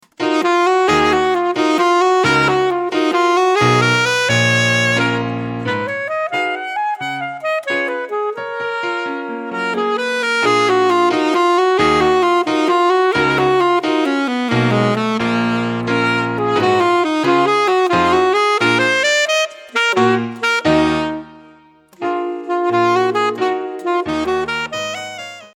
Etude pour Saxophone - Saxophone Alto ou Tenor et Piano